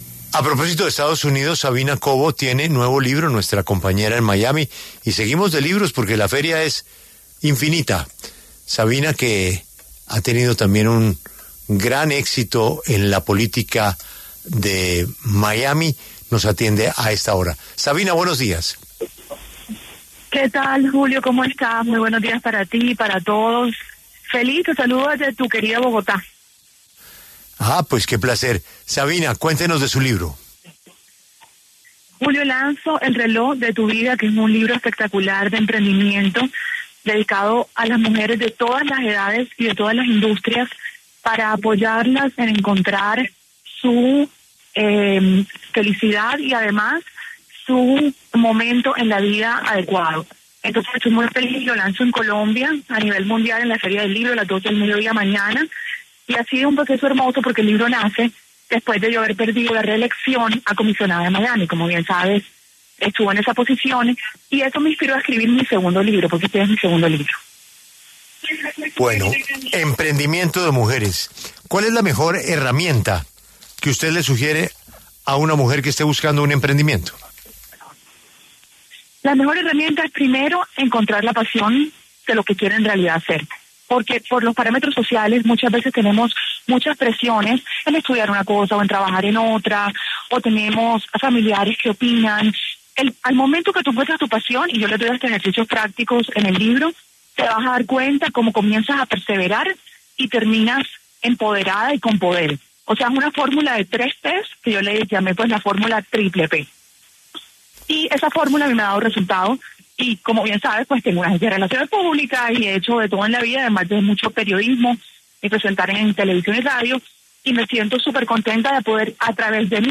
La periodista, escritora y emprendedora colombiana Sabina Covo pasó por los micrófonos de La W con Julio Sánchez Cristo para anunciar el lanzamiento de su nuevo libro, ‘El reloj de tu vida’, durante la Feria Internacional del Libro en Bogotá el próximo 10 de mayo.